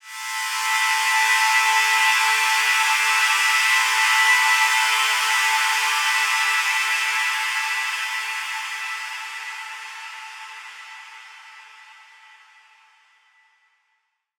SaS_HiFilterPad06-A.wav